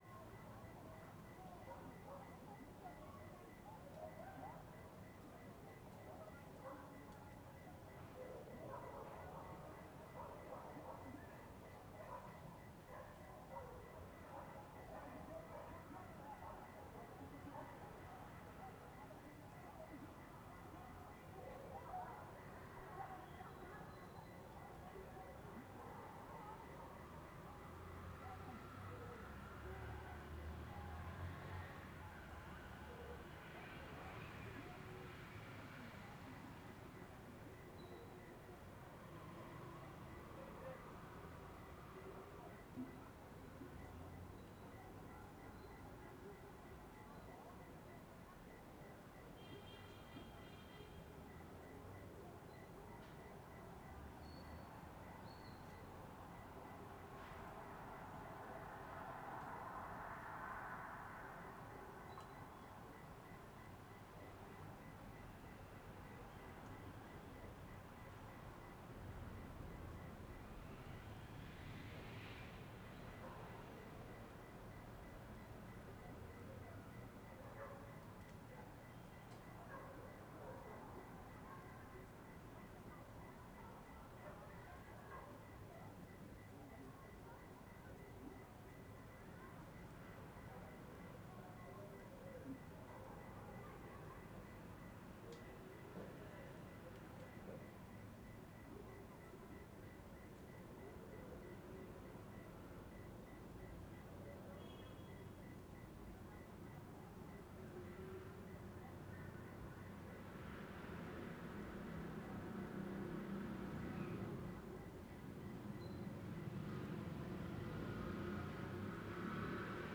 CSC-03-017-GV - Ambiencia no quintal de casa em Alto Paraiso ouvindo motos e cachorros ao fundo.wav